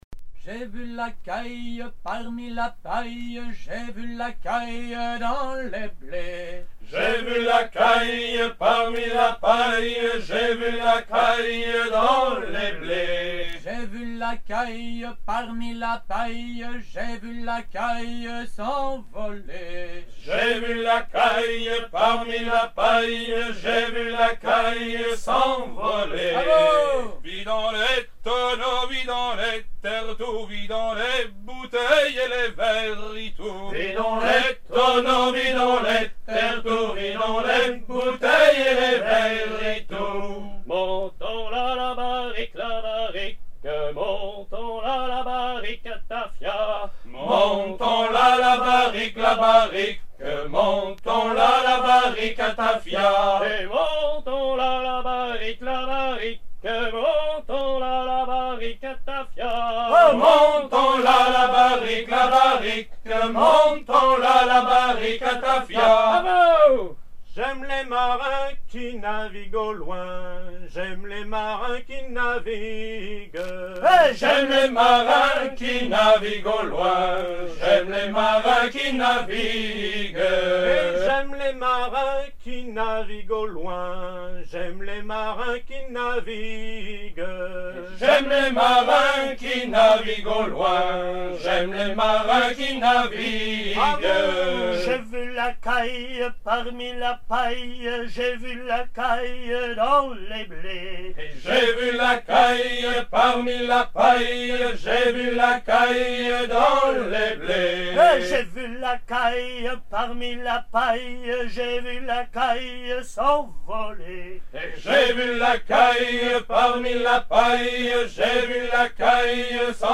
Chants à hisser main sur main recueillis en 1975 et 1976 a Fécamp et à Cancale
Catégorie Pièce musicale éditée